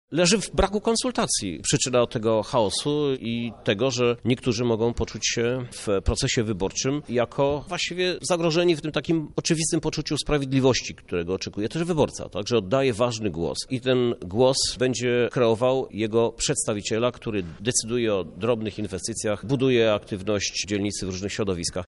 – Problemem jest brak współpracy – wyjaśnia prezydent Lublina – Krzysztof Żuk: